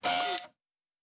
Servo Small 4_2.wav